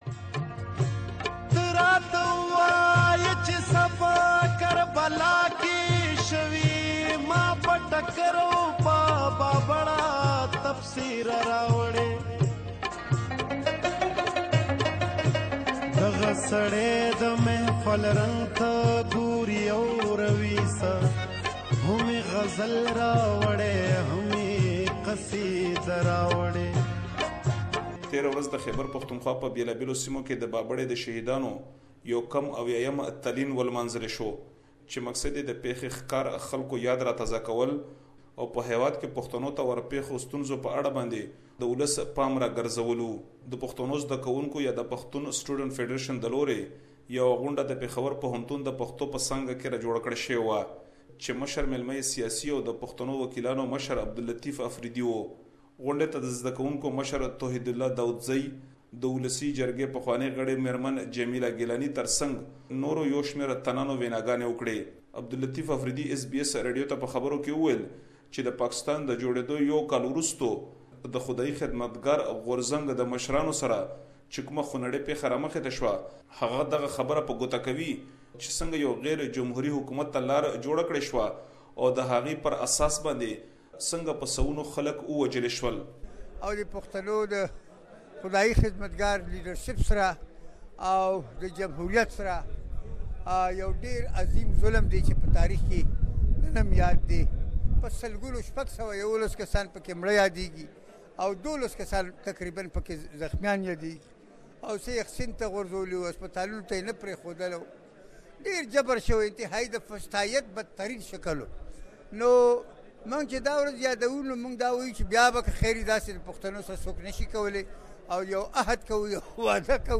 a report from one of the gatherings in KPK